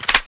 clipin4.wav